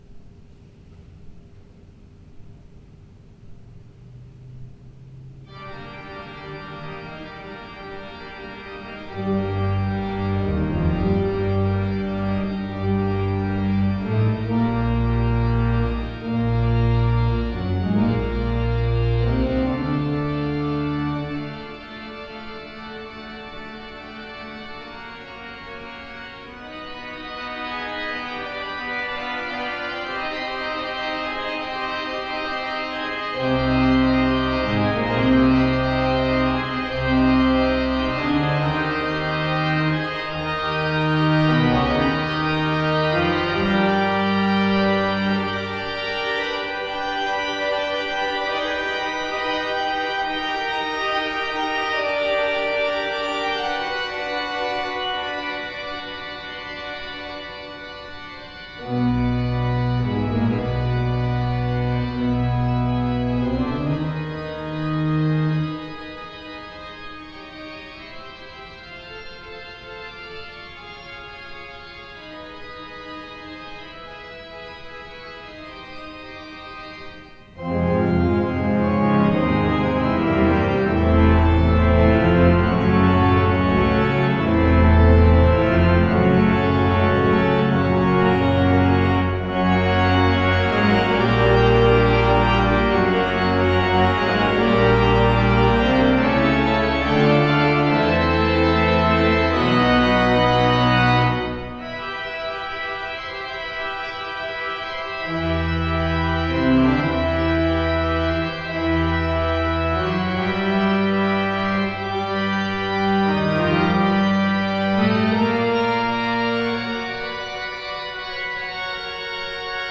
Postludes played at St George's East Ivanhoe 2013
The sound files listed below are not live service recordings due to obvious logistic difficulties. The recordings are taken from rehearsal tapes made in the week prior to the service in question.